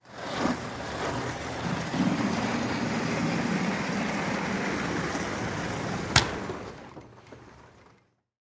Звуки ширмы
На этой странице собраны звуки традиционных японских ширм – редкие и атмосферные аудиозаписи.